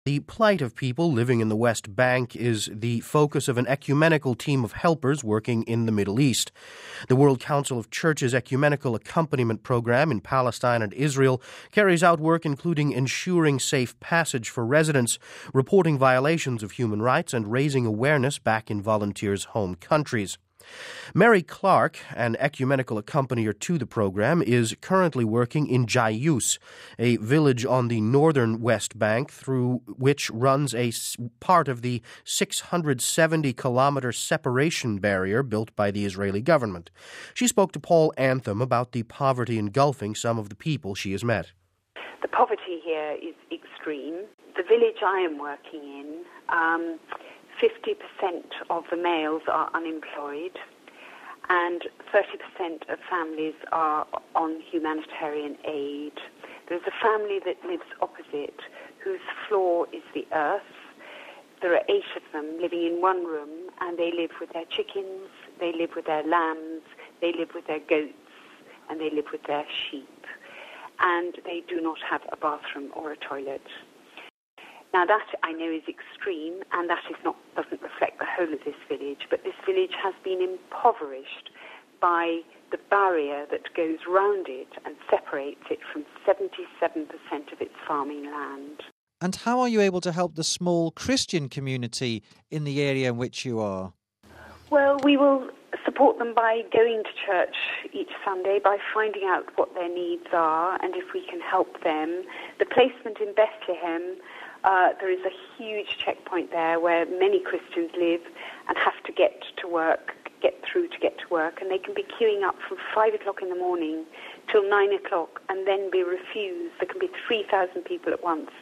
Accompanier Speaks Out About Programme